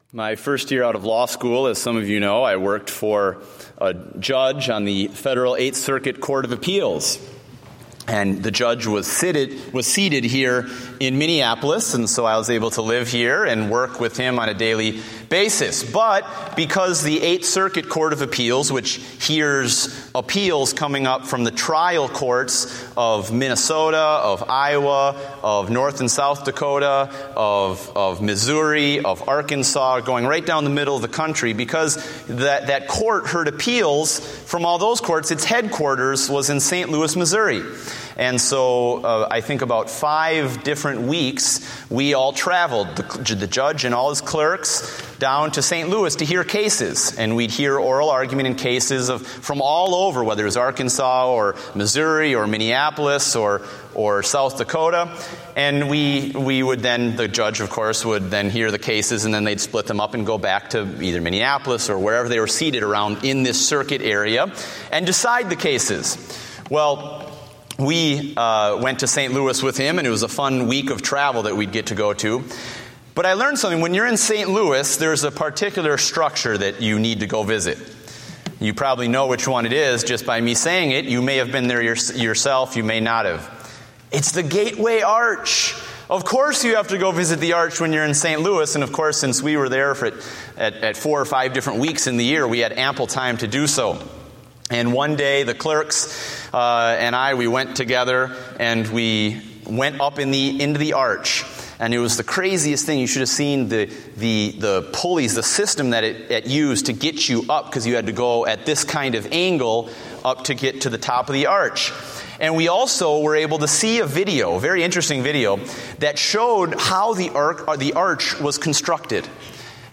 Date: June 1, 2014 (Evening Service)